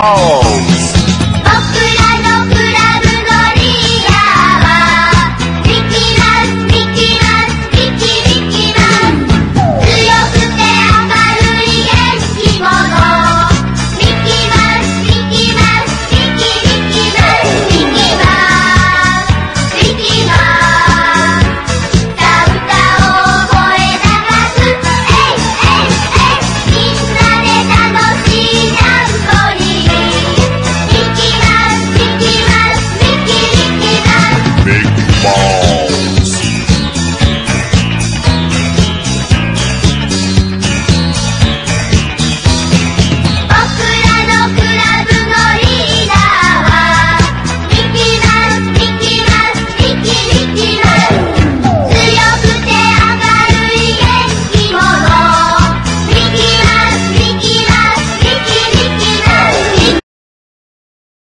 モーダル～フリー・インプロ〜ジャズ・ロックなどの東欧ジャズの熱気を詰め込んだ貴重音源2枚組！